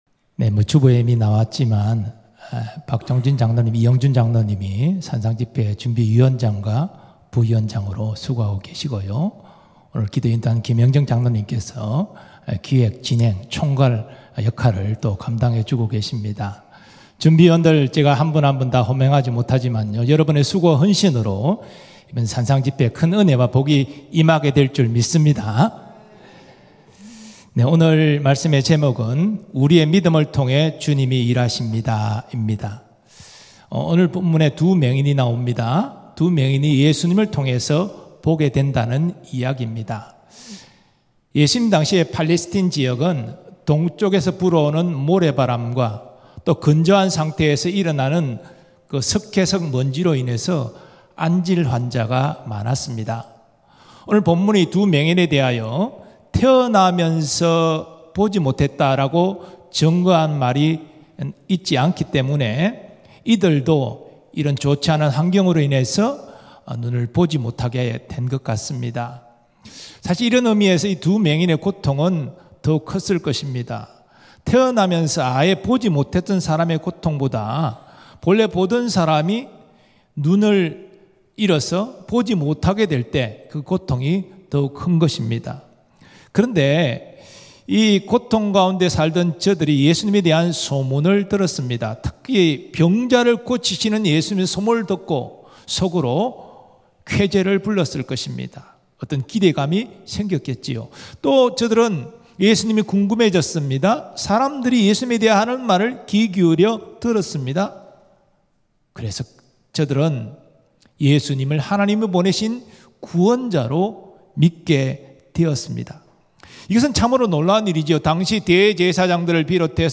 금요성령기도회
음성설교